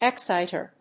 音節ex・cít・er 発音記号・読み方
/‐ṭɚ(米国英語), ‐tə(英国英語)/